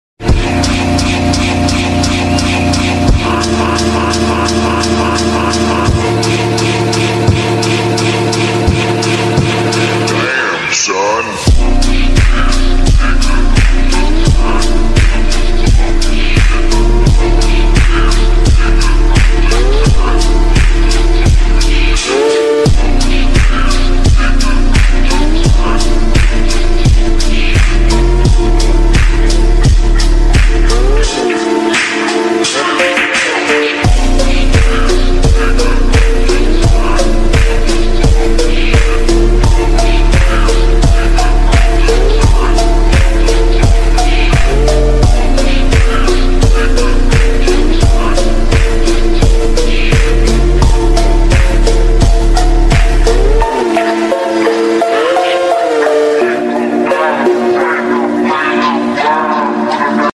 Audi Rs7 & Bmw M5 sound effects free download